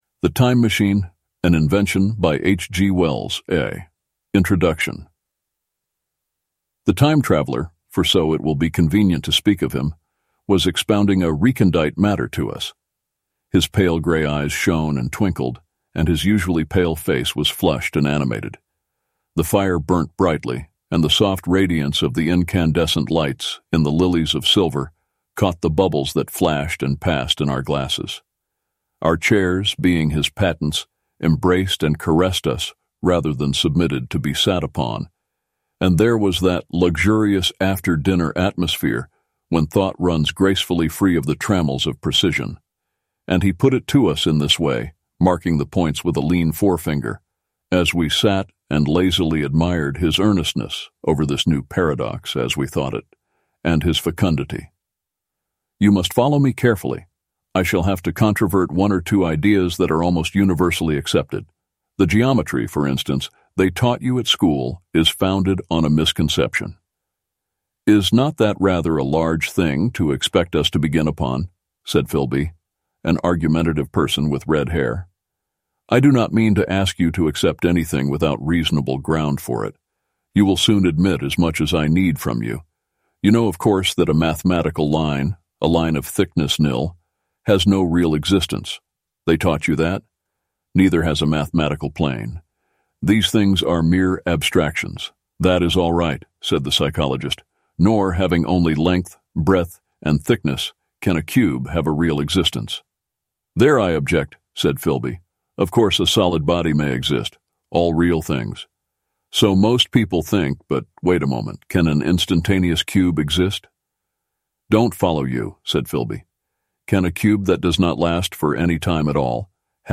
Elevenlabs is a lot closer to compelling audiobook narration (needs a better way to deal with multiple characters in a story without manual use of multiple voices):